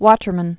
(wôtər-mən, wŏtər-)